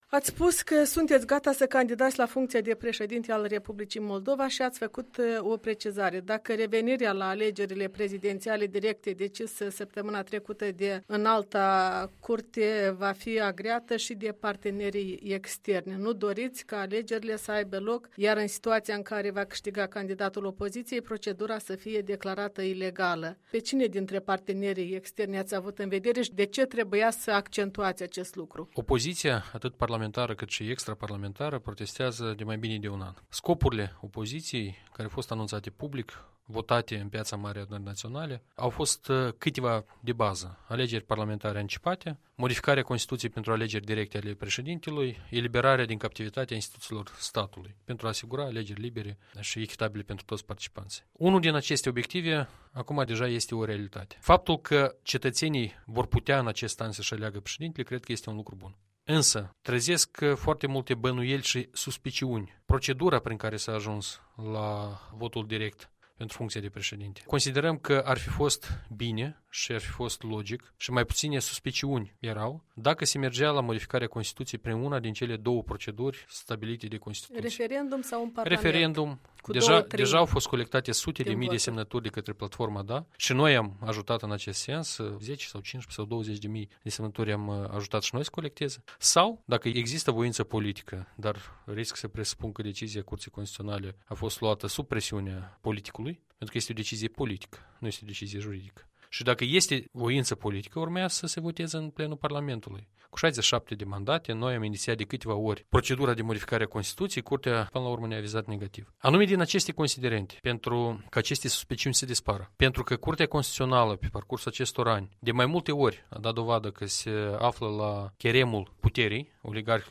Un interviu cu liderul socialist Igor Dodon după anunțul candidaturii sale la funcția de președinte al țării.